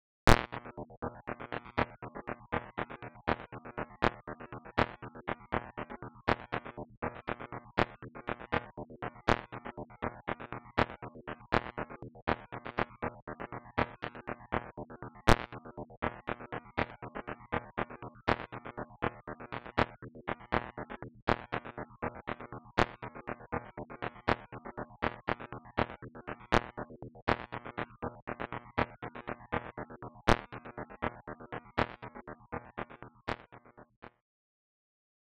Generic additive synthesis with a more complicated basis and a product combinator (p. 271)